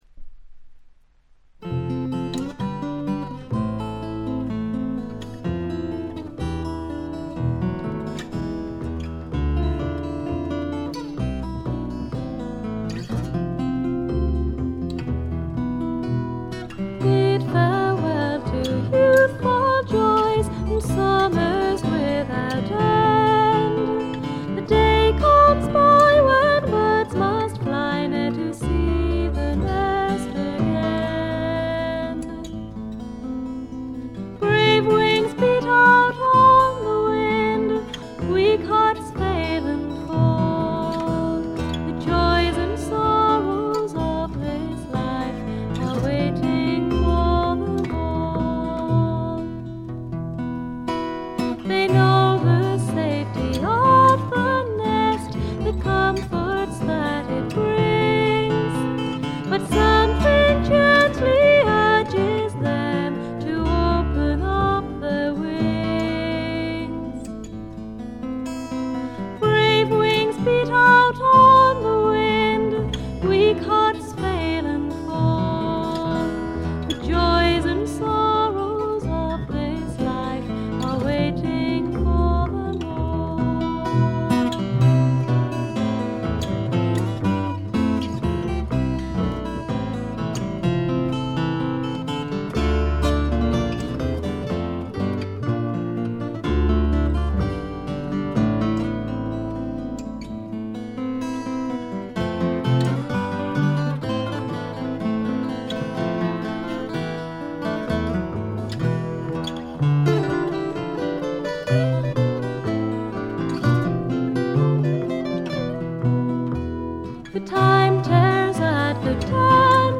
軽微なチリプチ少しだけ。
ほとんどこの二人だけで演奏しておりかなりの腕達者です。
オールドタイミーなグッタイム・ミュージック好きな方やフィメールものがお好きな方ならばっちりでしょう。
試聴曲は現品からの取り込み音源です。